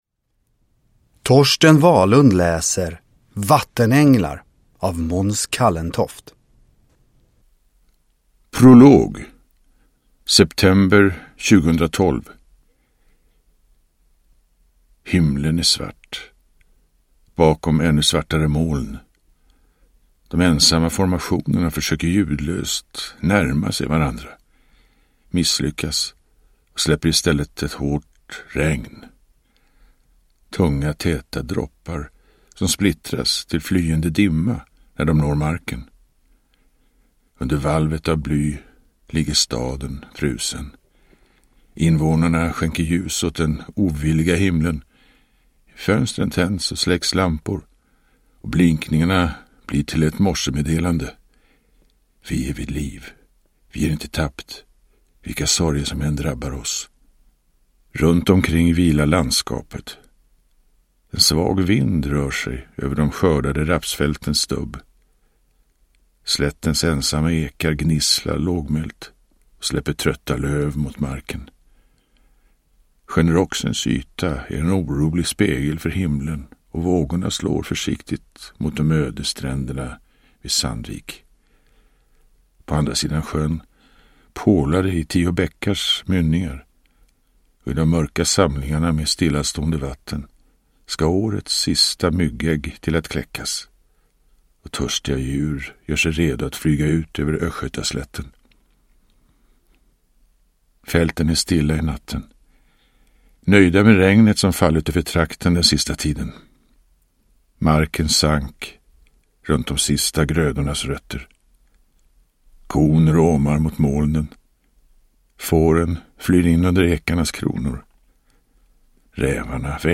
Uppläsare: Torsten Wahlund
Ljudbok